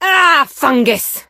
rosa_death_02.ogg